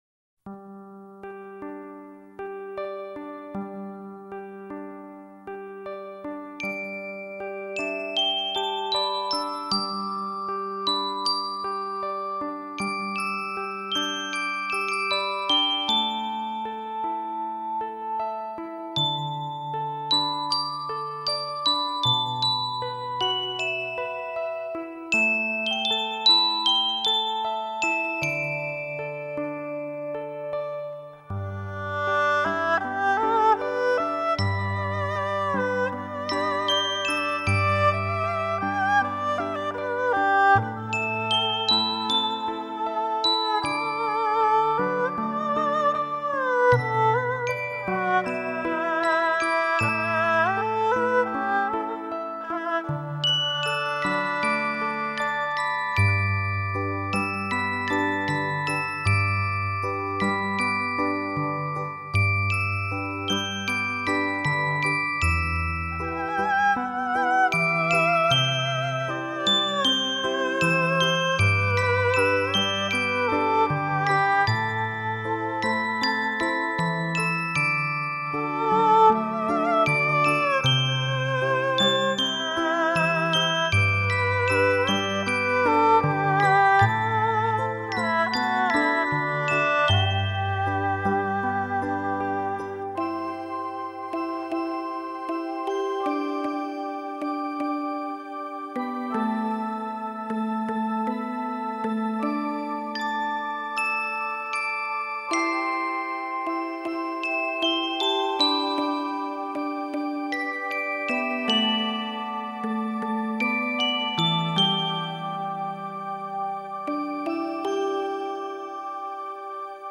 水晶琴闪烁着最清心无欲的音符，以简单、真朴的旋律，